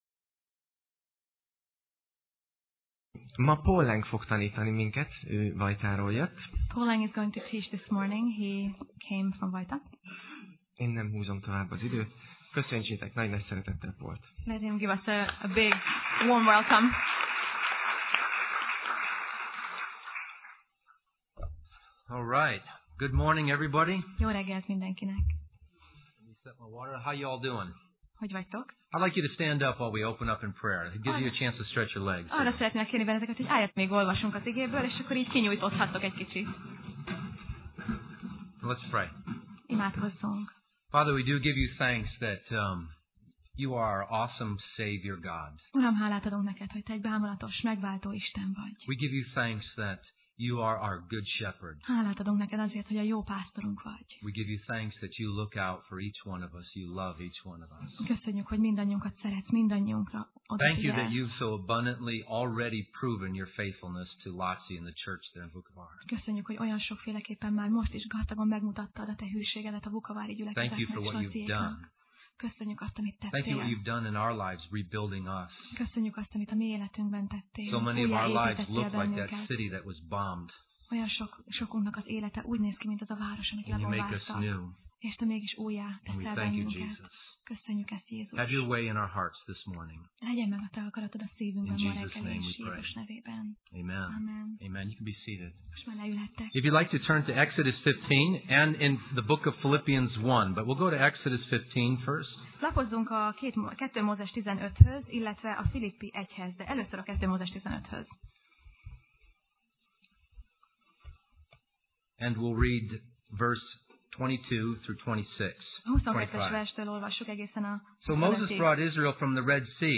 Sorozat: Tematikus tanítás Passage: 2Mózes (Exodus) 15:22 Alkalom: Vasárnap Reggel